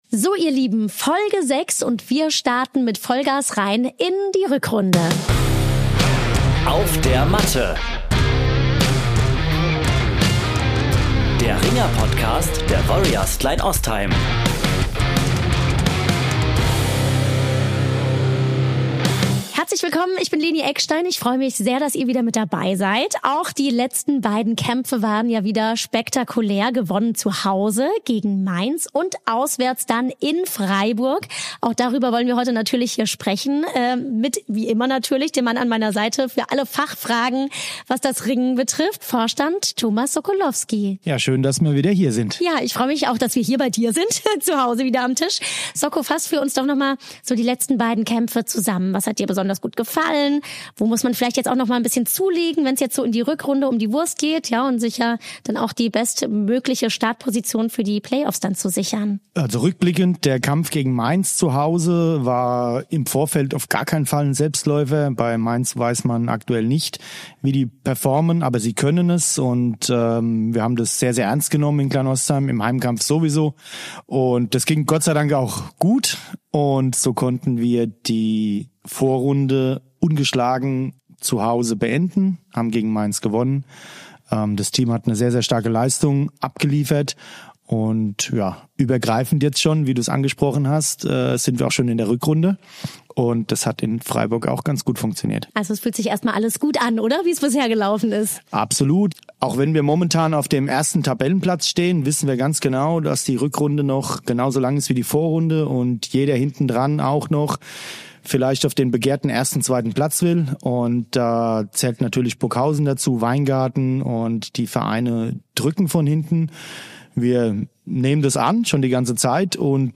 Ein Gespräch über Ziele, Leidenschaft und persönliche Entwicklung.